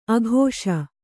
♪ akṣa